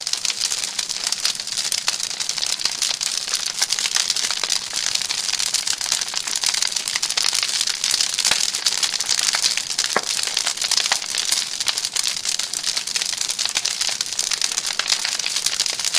Fire1.ogg